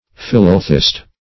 philalethist - definition of philalethist - synonyms, pronunciation, spelling from Free Dictionary
Search Result for " philalethist" : The Collaborative International Dictionary of English v.0.48: Philalethist \Phil`a*le"thist\, n. [Philo- + Gr.